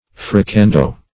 Meaning of fricando. fricando synonyms, pronunciation, spelling and more from Free Dictionary.